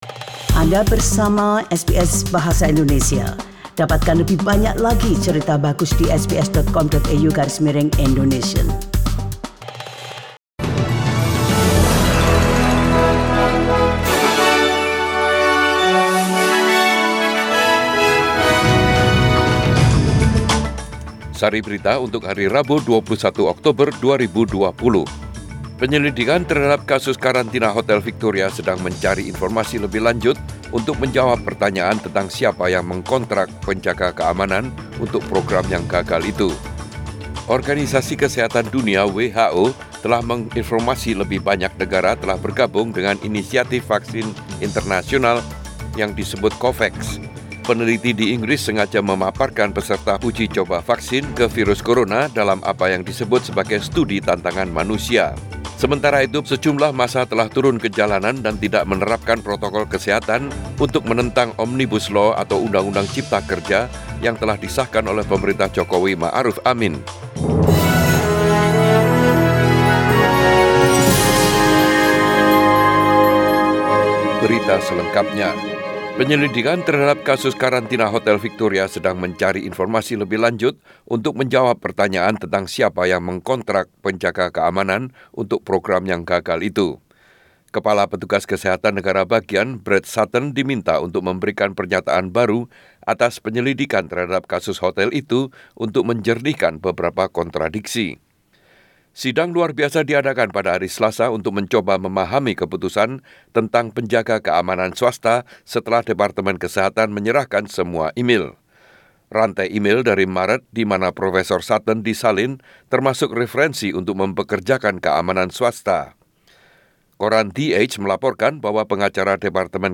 SBS Radio News in Bahasa indonesia -21 October 2020